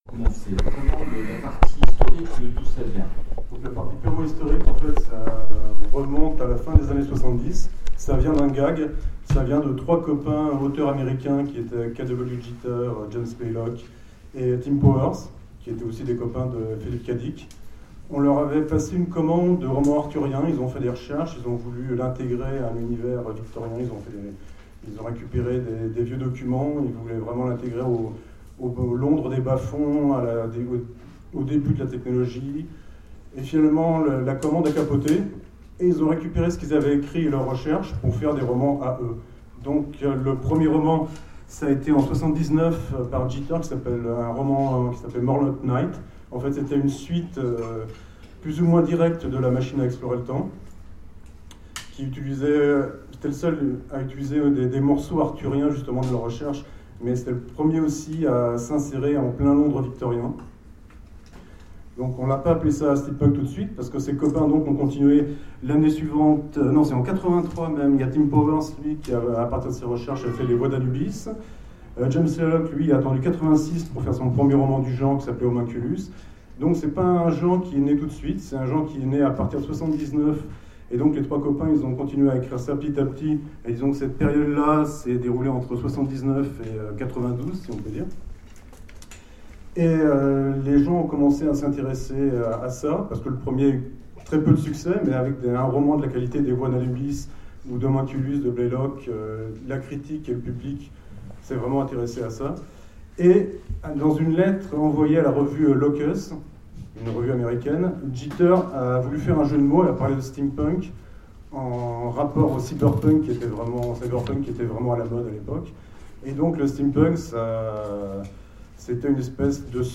Sèvres 2015 : Conférence Le steampunk
rencontres_sevres_2015_conference_Steampunk_ok.mp3